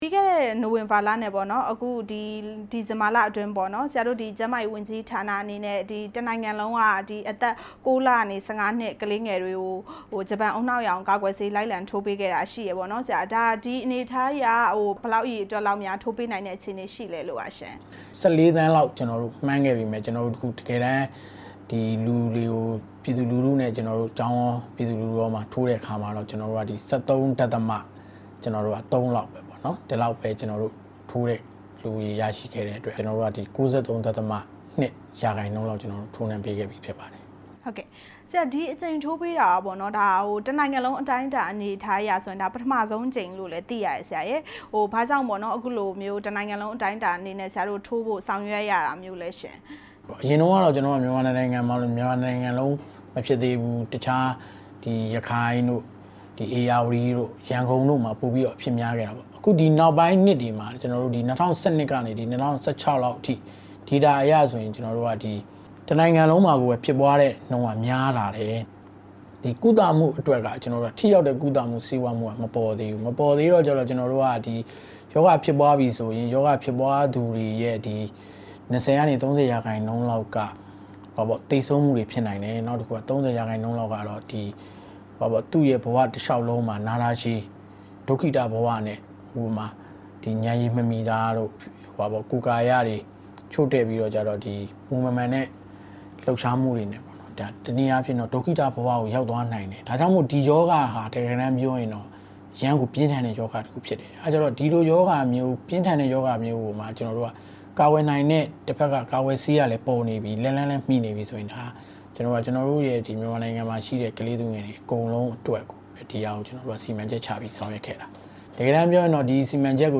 ဂျပန်ဦးနှောက်ရောင်ရောဂါ ကာကွယ်ဆေးအကြောင်း မေးမြန်းချက်